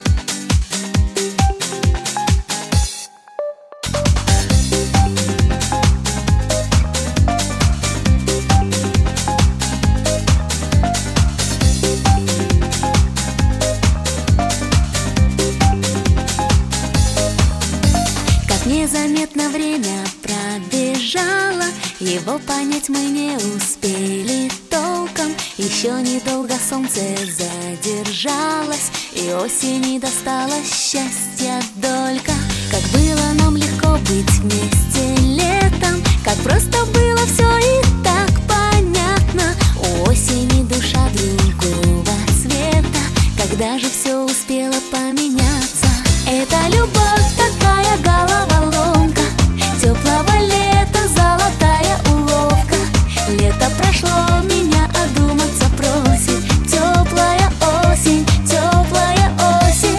Pop
44.1 kHz, Stereo